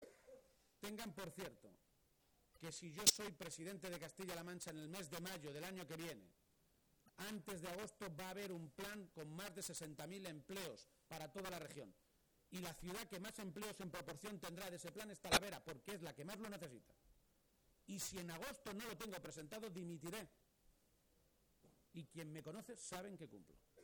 El secretario general del PSOE de Castilla-La Mancha, Emiliano García-Page, ha visitado hoy la Feria de Talavera y allí, a 24 horas de hacer oficial su candidatura a las primarias para ser quien compita por la Presidencia de Castilla-La Mancha, ha hecho un contundente anuncio:”Si en Mayo soy el Presidente de Castilla-La Mancha y antes de Agosto no hay encima de la mesa un Plan de Empleo para 60.000 parados, yo dimitiré”.